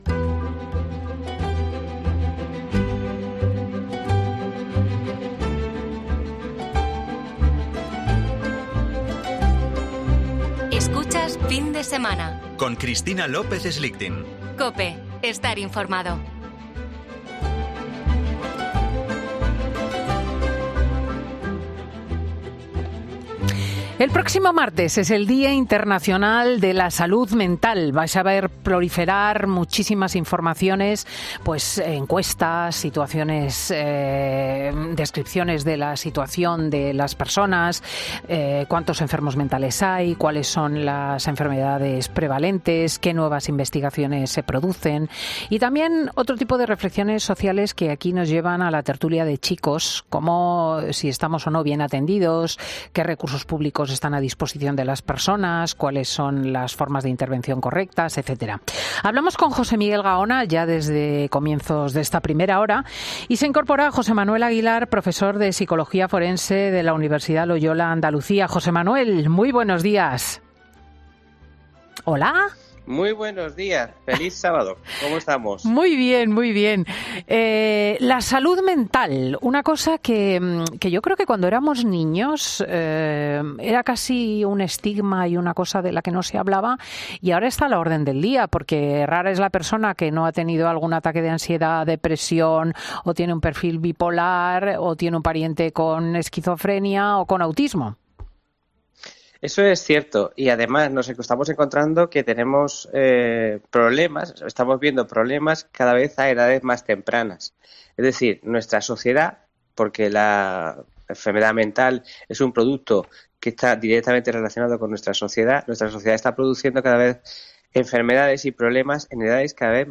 En la tertulia de Fin de Semana se ha puesto sobre la mesa el panorama actual de la salud mental, y lo hemos hecho de la mano de dos expertos.